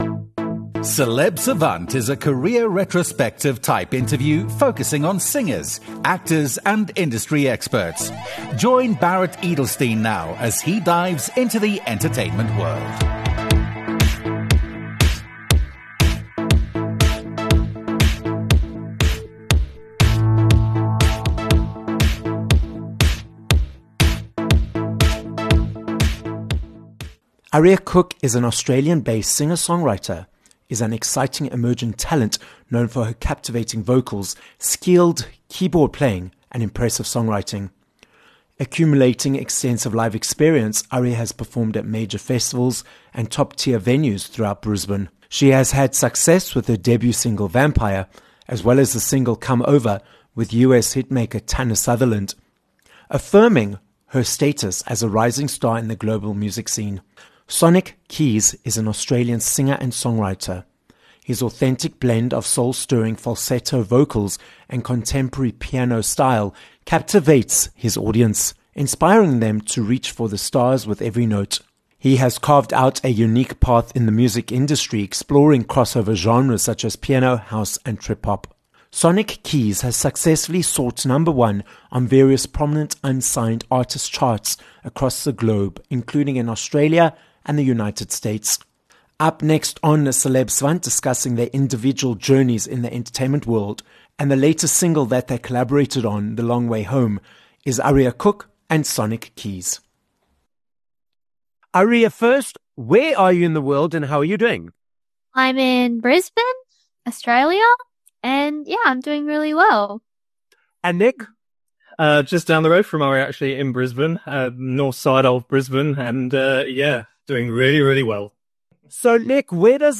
two Australian singers and songwriters